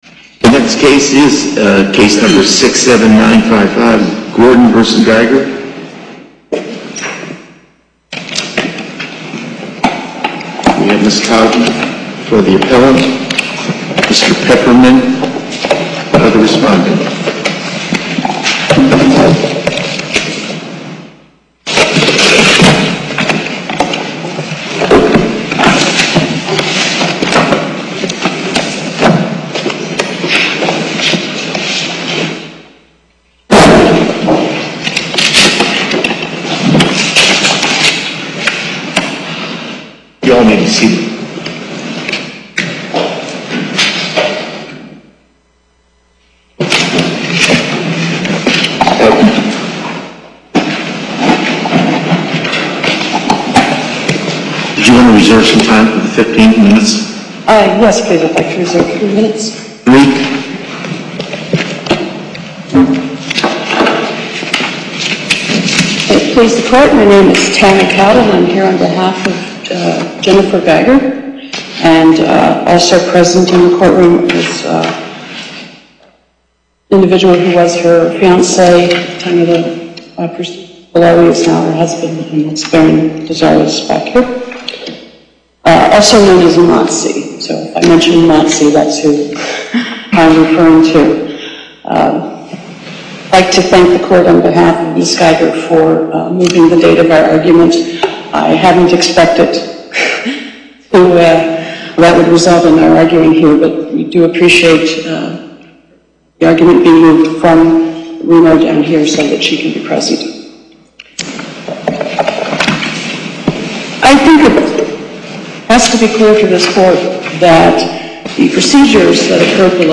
Location: Las Vegas Before the En Banc Court, Chief Justice Cherry presiding